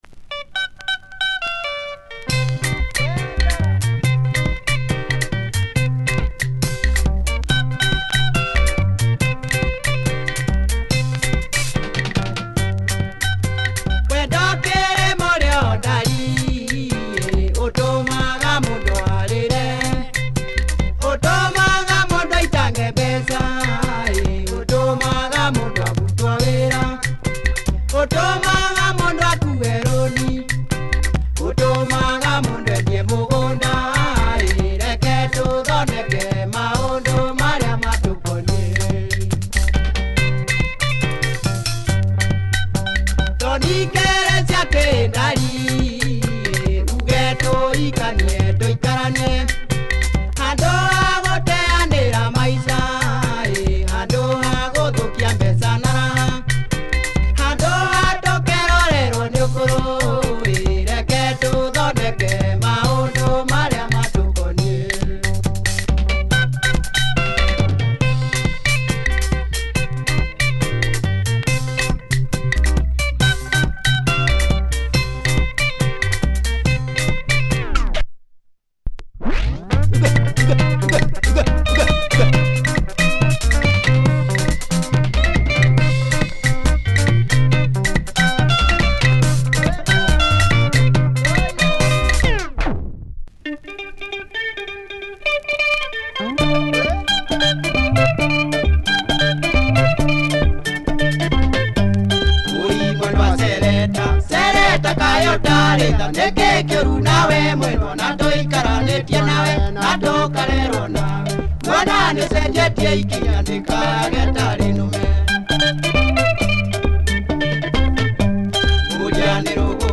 Killer Kikuyu Benga disco